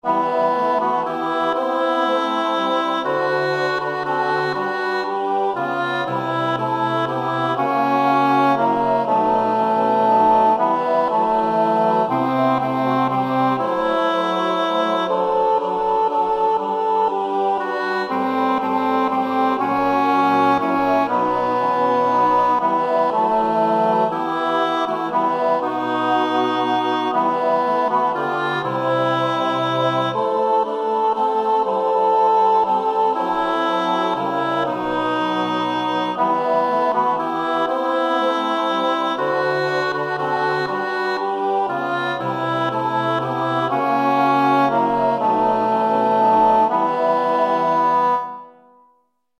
Ande och Liv alt
ande av liv_alt.mp3